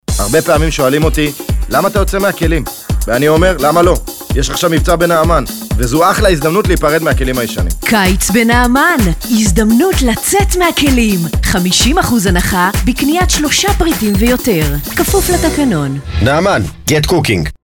נעמן- לצאת מהכלים – קריינית 3